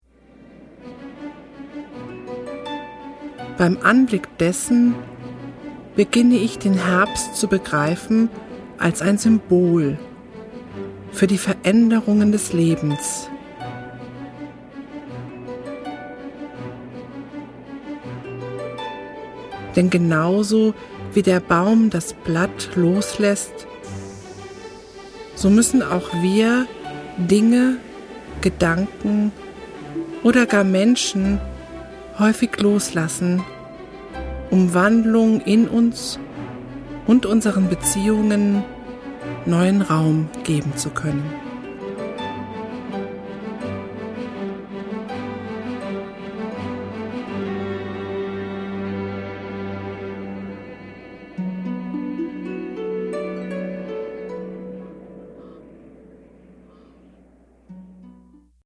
iPhone / iPad / iPod Vorschau Der Herbst (Ton)
Wir laden Sie ein auf eine meditative Reise mit einfühlsamen Texten und wunderschöner Musik durch die vier Jahreszeiten!
Herbststimmung | Falltime feeling